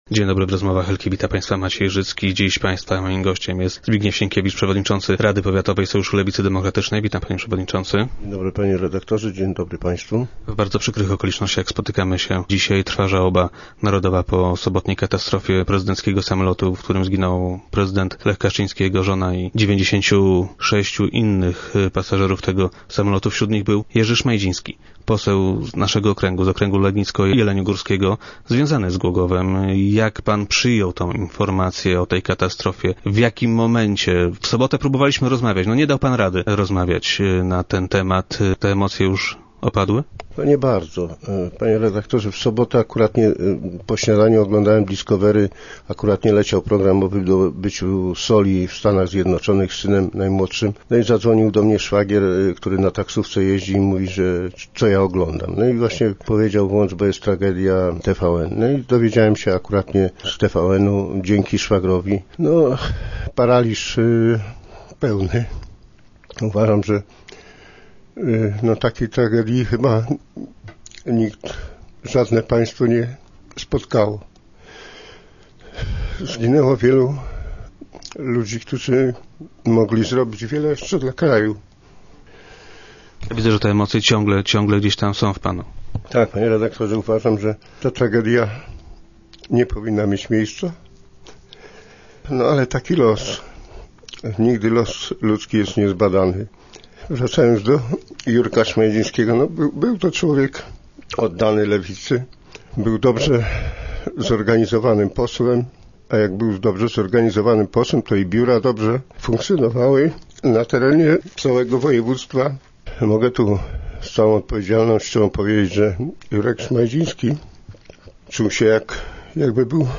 mówił, łamiącym się głosem